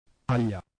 [ ʎ ]
U028E Palatal non-fricative lateral.